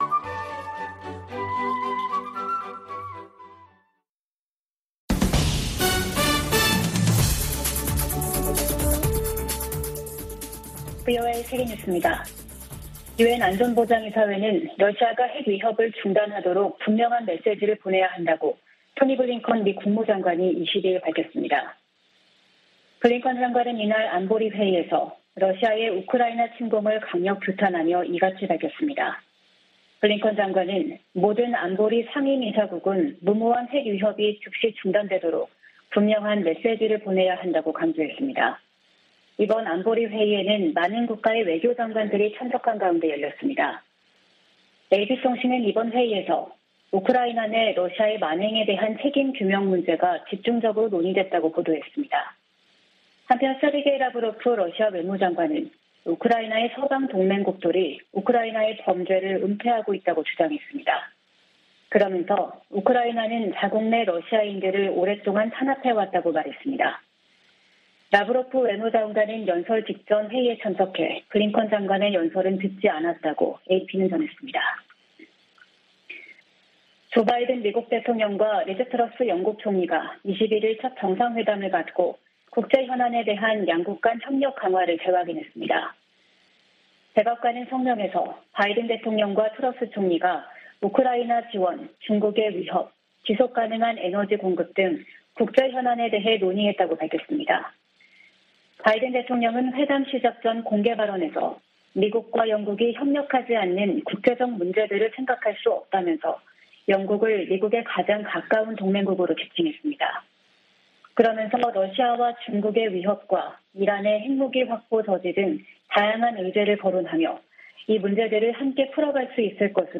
VOA 한국어 아침 뉴스 프로그램 '워싱턴 뉴스 광장' 2022년 9월 23일 방송입니다. 조 바이든 대통령과 윤석열 한국 대통령이 유엔총회가 열리고 있는 뉴욕에서 만나 북한 정권의 위협 대응에 협력을 재확인했습니다. 한일 정상도 뉴욕에서 대북 협력을 약속하고, 고위급 외교 채널을 통해 양국 관계 개선 노력을 가속화하기로 합의했습니다. 바이든 대통령은 유엔총회 연설에서 북한의 지속적인 유엔 제재 위반 문제를 지적했습니다.